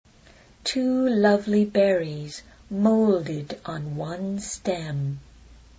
Location: USA
How do you pronounce this word?